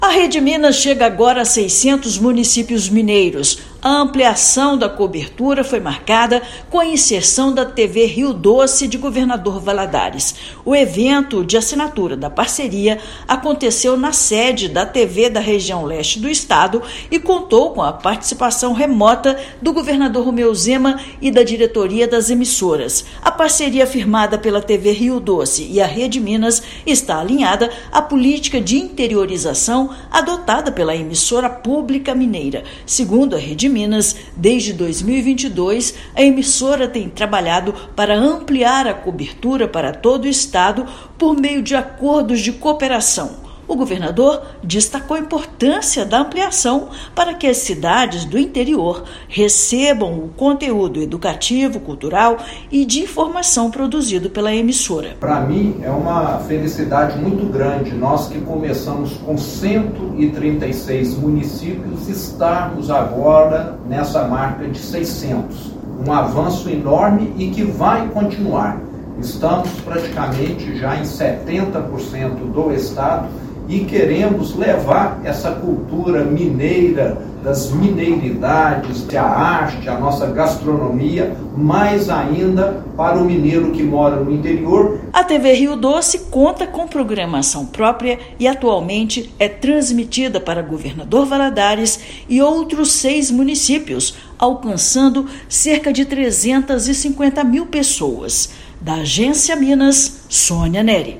Contrato, que expande o alcance da emissora no interior do estado, foi firmado nesta sexta-feira (17/1). Ouça matéria de rádio.